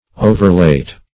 Overlate \O"ver*late"\, a. Too late; exceedingly late.